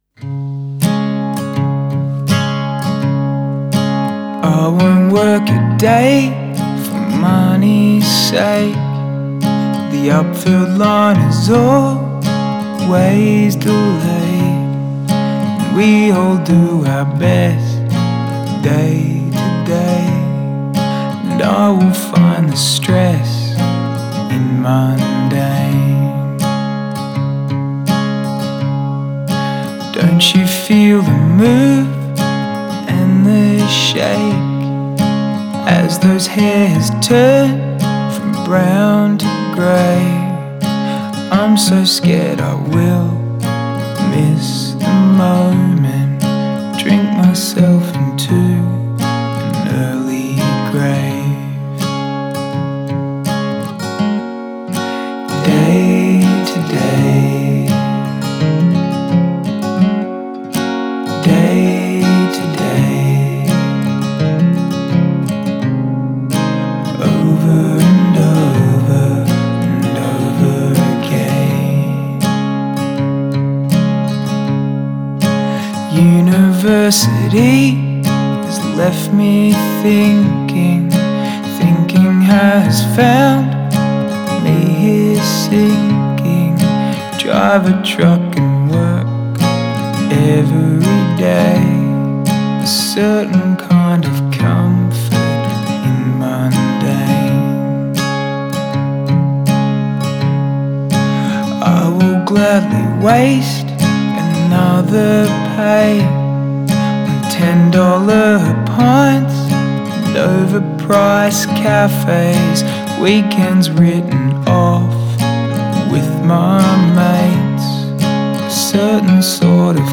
indie pop band
gloriously melancholy and melodic